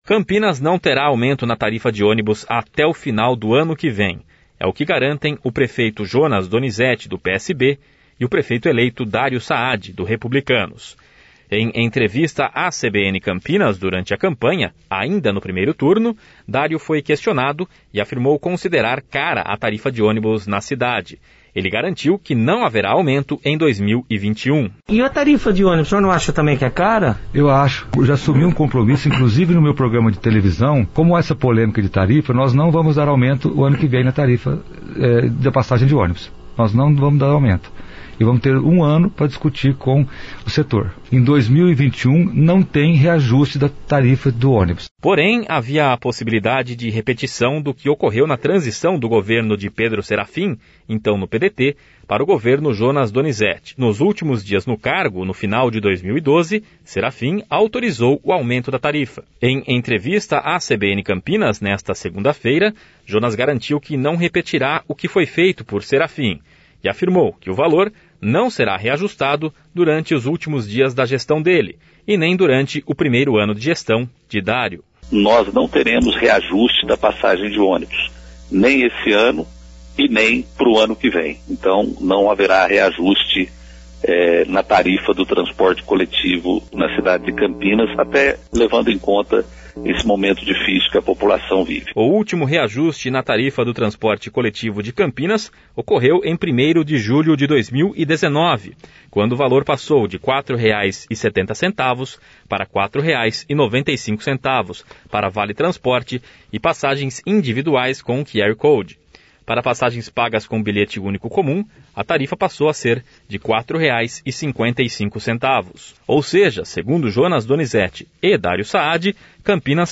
Em entrevista à CBN Campinas nesta segunda-feira (28), Jonas garantiu que não repetirá o que foi feito por Serafim, e afirmou que o valor não será reajustado durante os últimos dias da gestão dele.